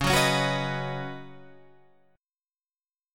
C#9sus4 chord